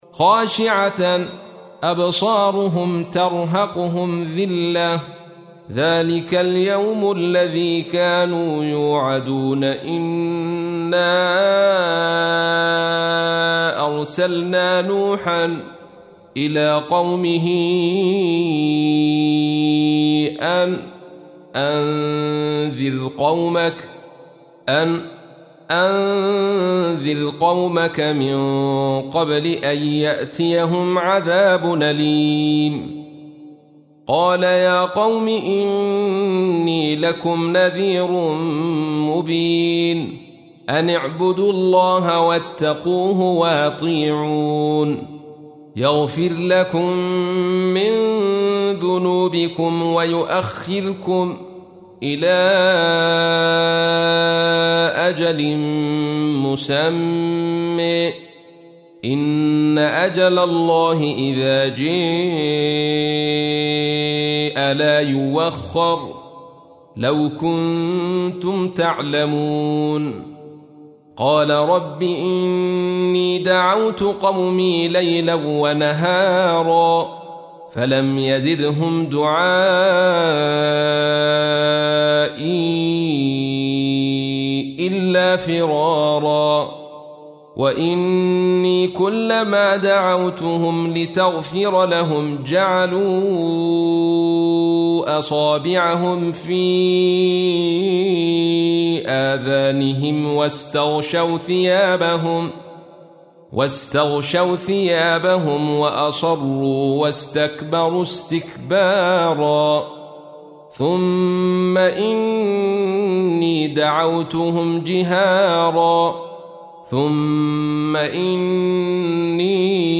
تحميل : 71. سورة نوح / القارئ عبد الرشيد صوفي / القرآن الكريم / موقع يا حسين